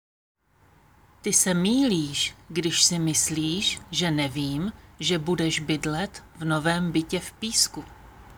Děkuji za zájem o nahrávku výslovnost I a Y - Ty se mýlíš - pomalu
Tady si můžete stáhnout audio na výslovnost I a Y: Ty se mýlíš – pomalu.
Ty-se-mýlíš_pomalu_lektorka.m4a